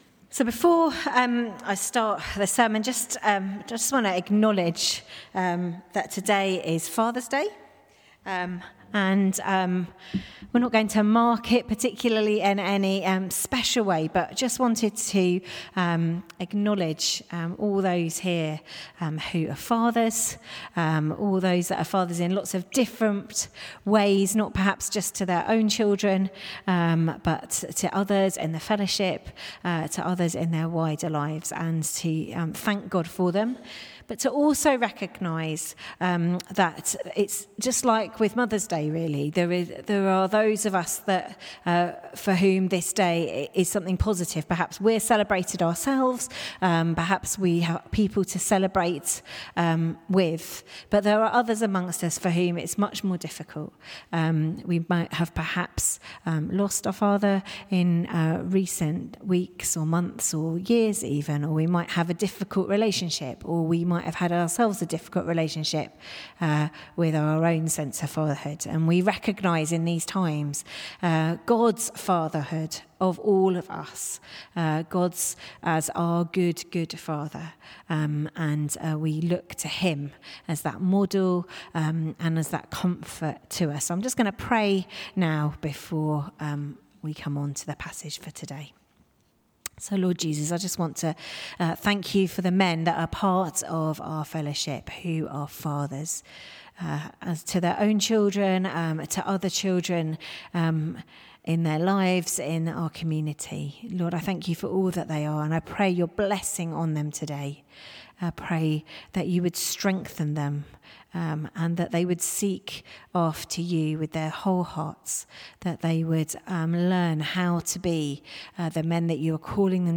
Sermon 15th June 2025 – Borehamwood Baptist